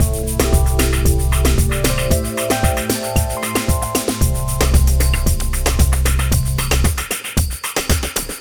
Ala Brzl 2 Full Mix 1a-C.wav